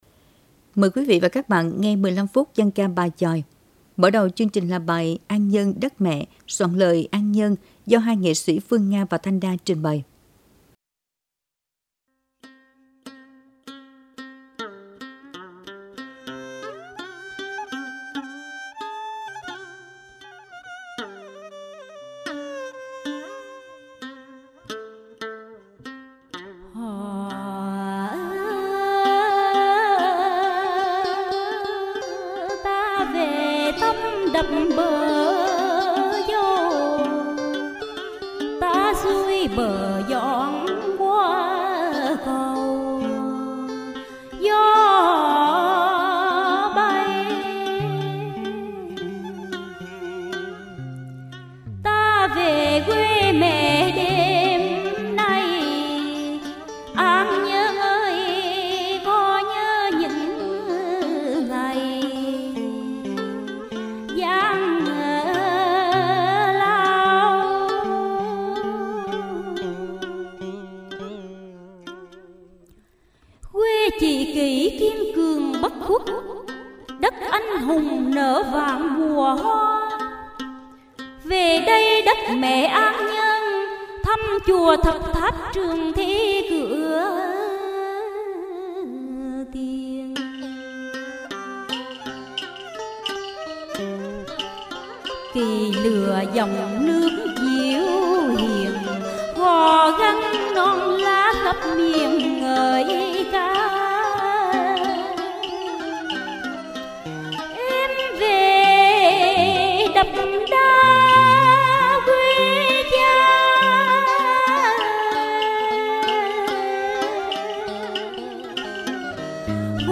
10-6-dan-ca-bai-choi_1.mp3